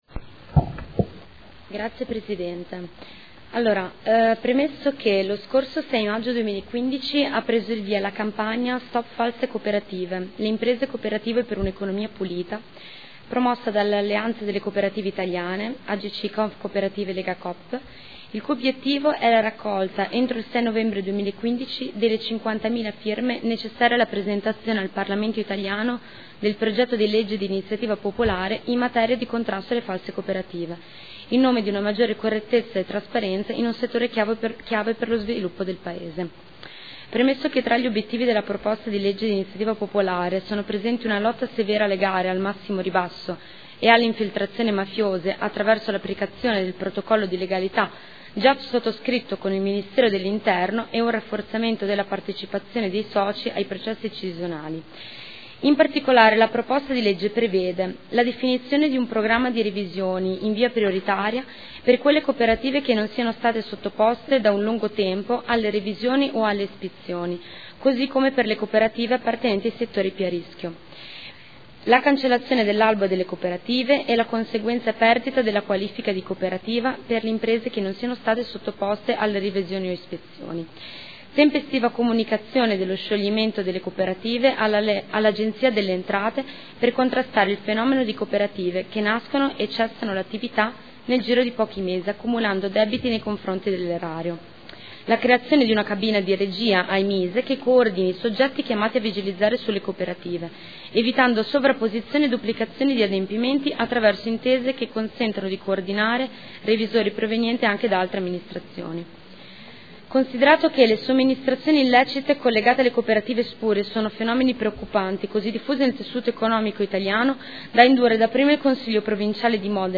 Federica Venturelli — Sito Audio Consiglio Comunale